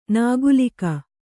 ♪ nāgulika